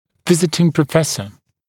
[‘vɪzɪtɪŋ prə’fesə][‘визитин прэ’фэсэ]приглашённый профессор